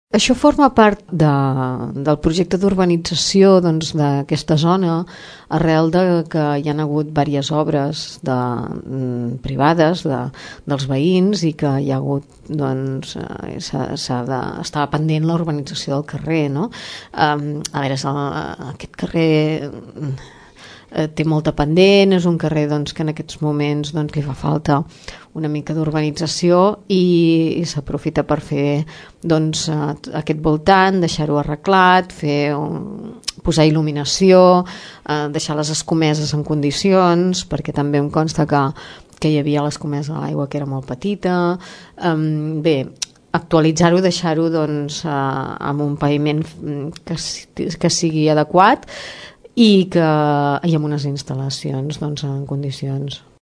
Les obres, que tindran un període d’execució de tres mesos, implicaran un canvi de sentit en el trànsit, que afectarà els carrers Sant Antoni i Prat de la Riba. La regidora d’urbanisme, Rosa Salarichs, detalla el contingut de les obres.